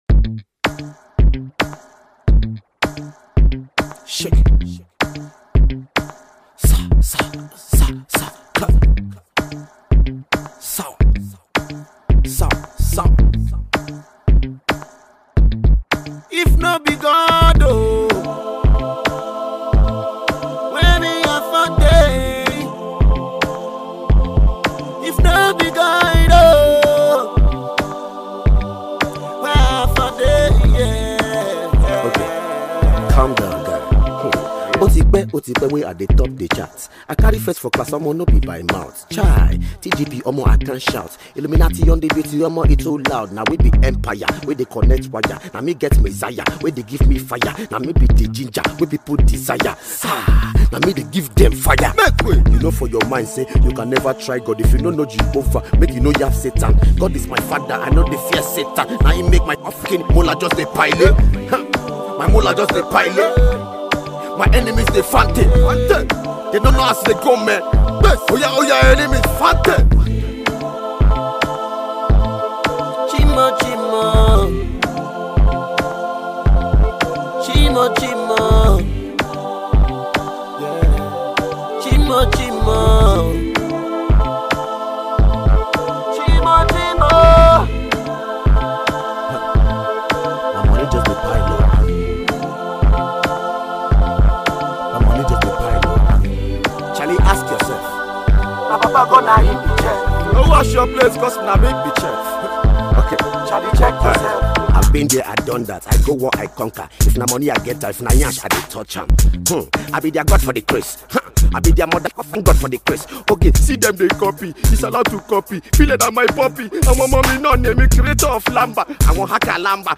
Get your headset, Listen and Enjoy the new praise jam below.